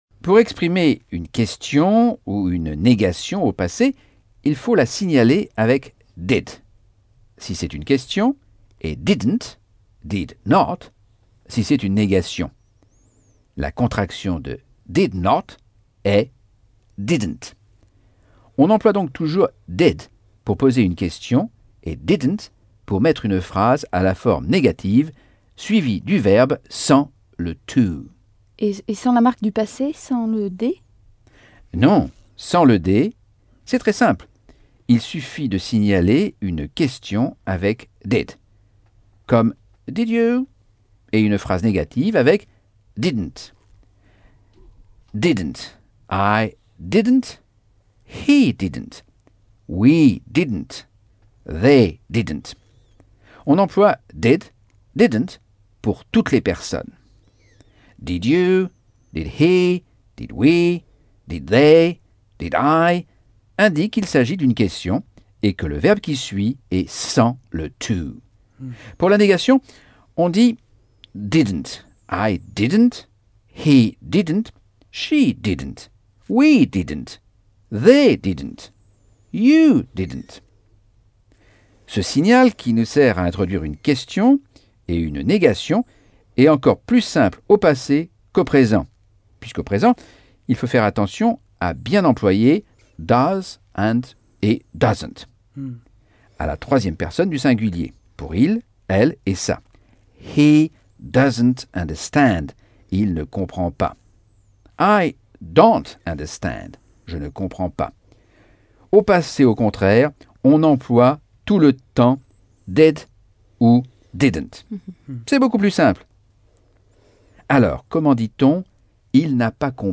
Leçon 10 - Cours audio Anglais par Michel Thomas - Chapitre 5